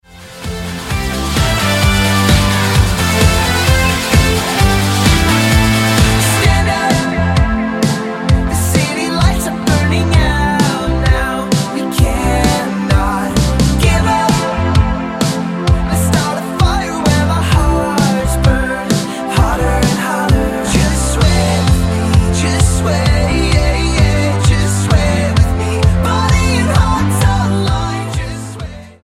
'80s-influenced synth pop sound
Style: Rock